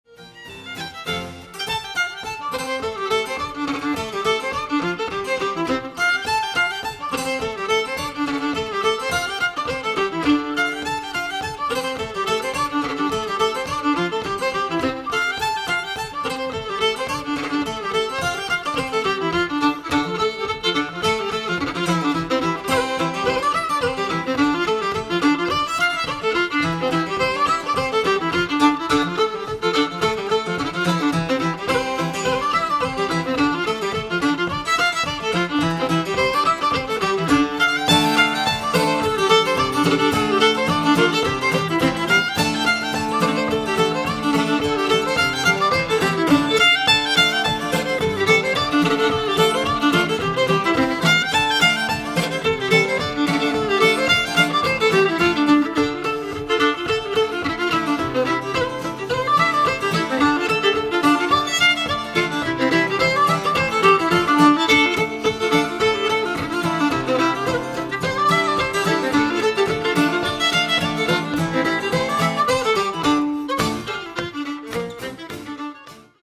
violins
guitars
harpsichord.
reel